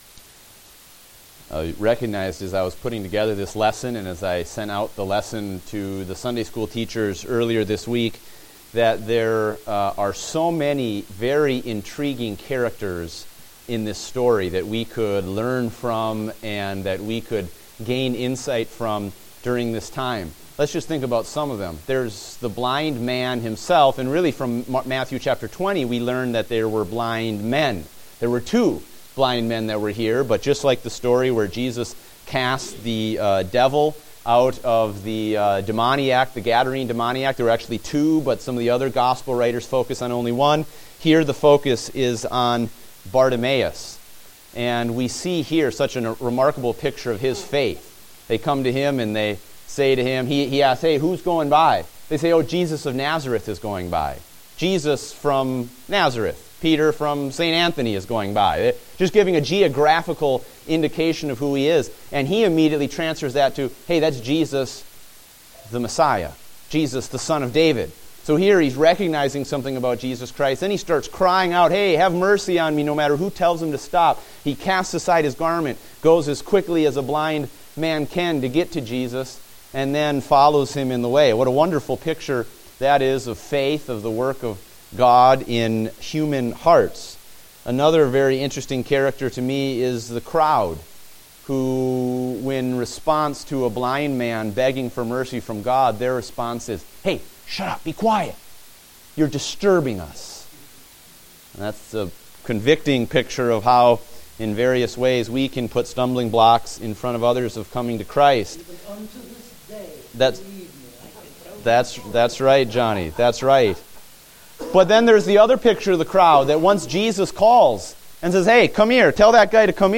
Date: January 10, 2016 (Adult Sunday School)